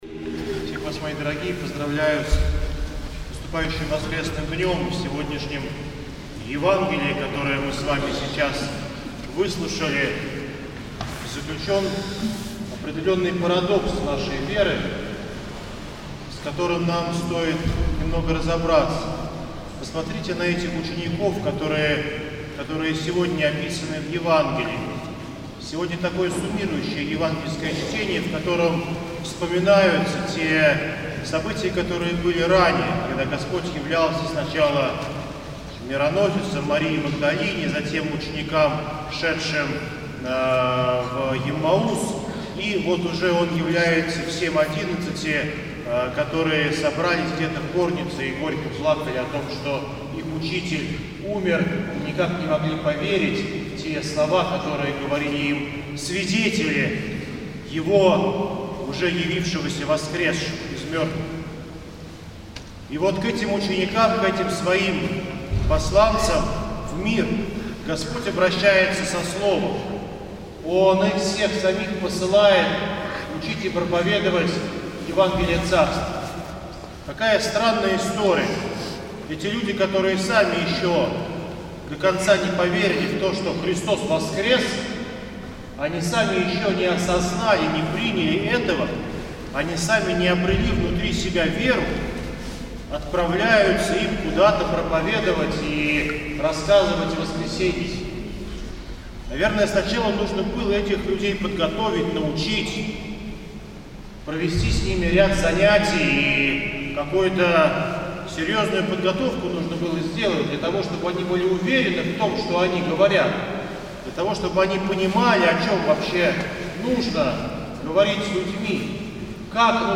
НОВОСТИ, Проповеди и лекции